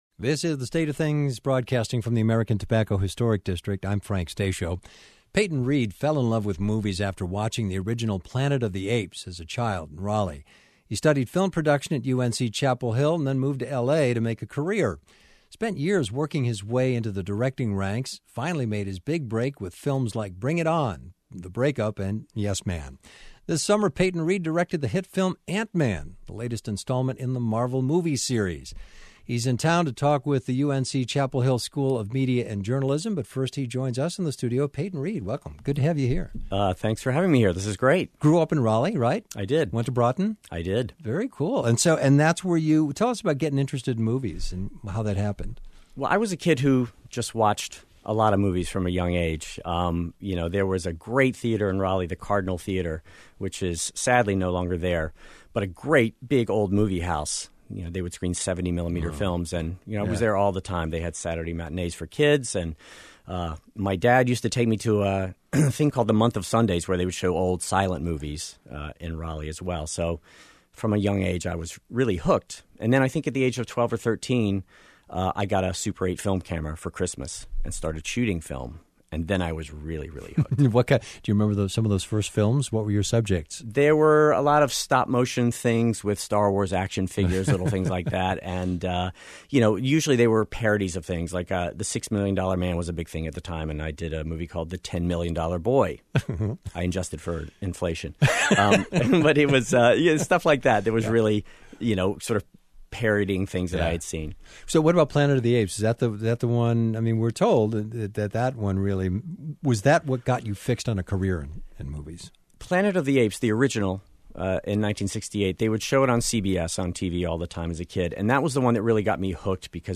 A conversation with director Peyton Reed